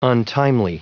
Prononciation du mot : untimely